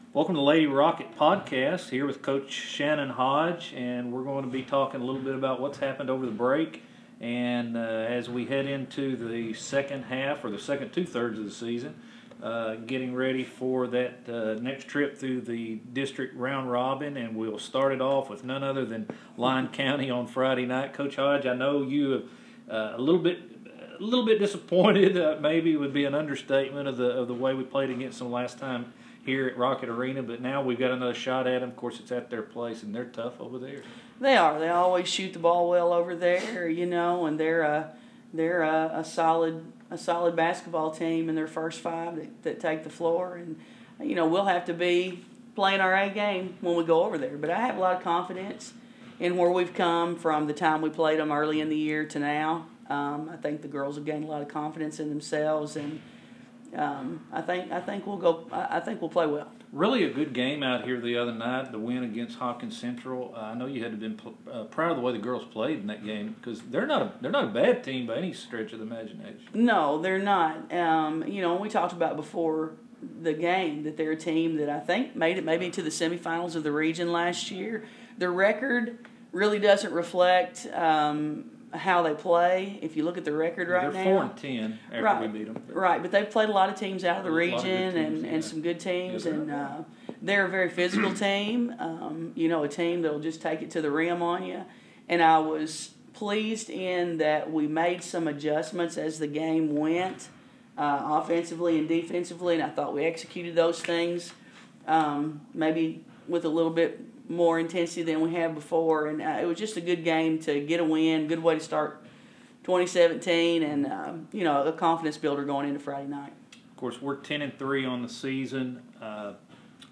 PODCAST: Post Game comments from the two coaches